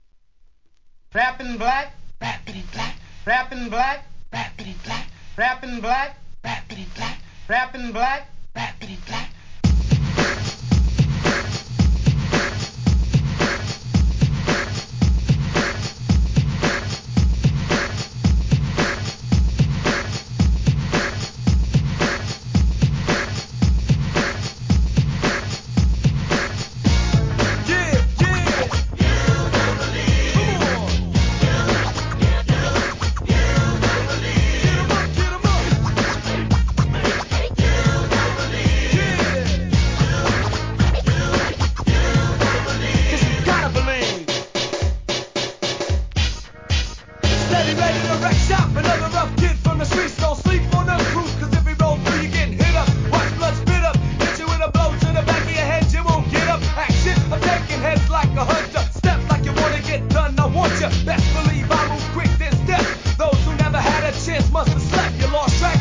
HIP HOP/R&B
(112 BPM)